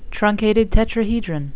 (trun-cat-ed   tet-tra-he-dron)